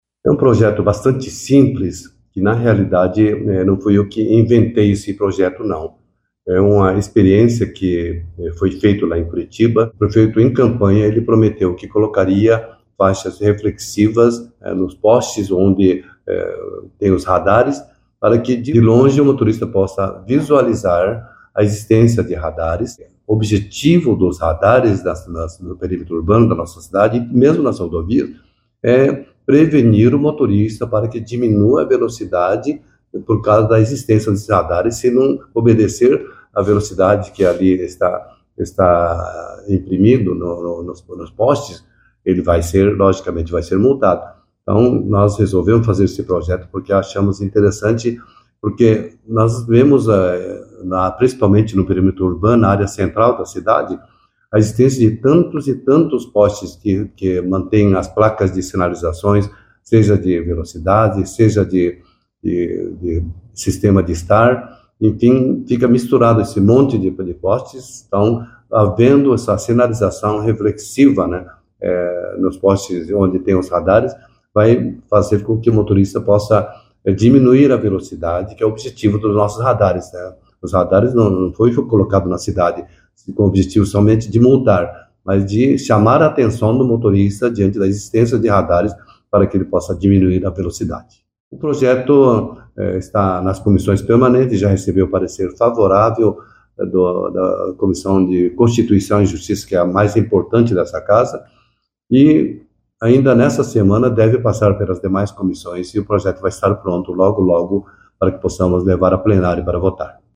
Ouça o que diz o vereador Mário Hossokawa.